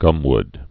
(gŭmwd)